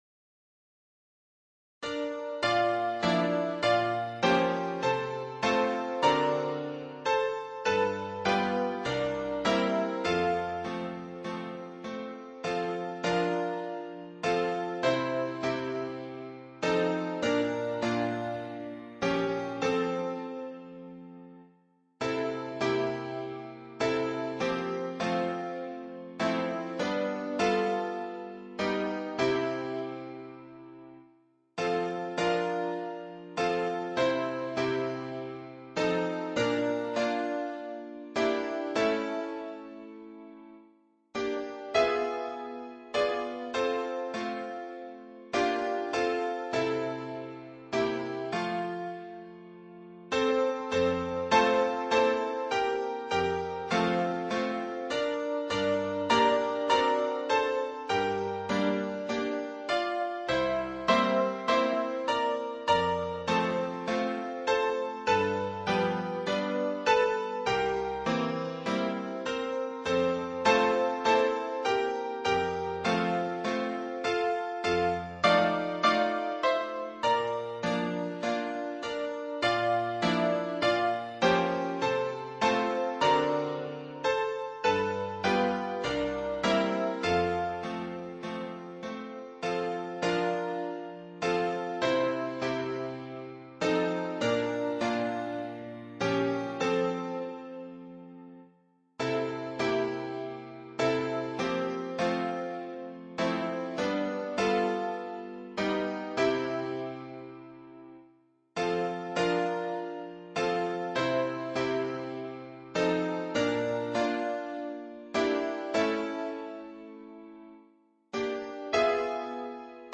这首歌的正歌是四部合唱，副歌以女中音为主旋律，曾感动无数信徒，特别是青年人把自己的生命交给为他们流血舍命的主耶稣，走上克己牺牲的道路。